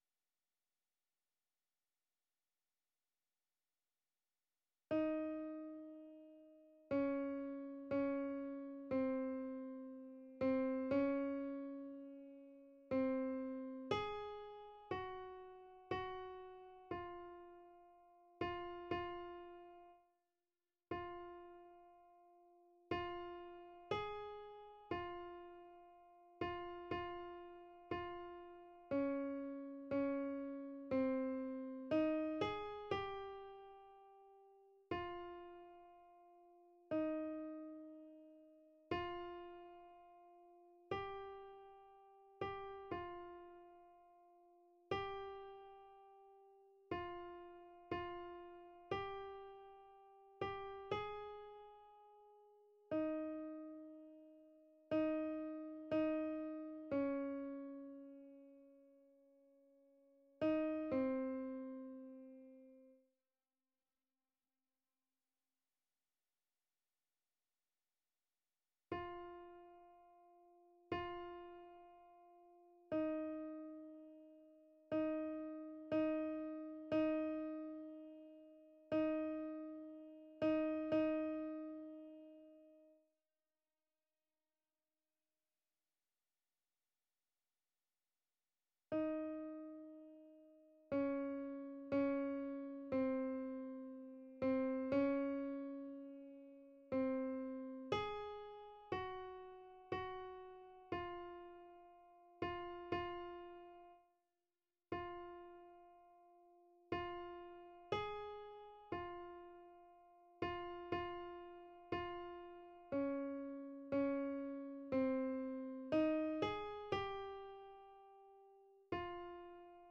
Répétition SATB par voix
Panis angelicus_alto,.mp3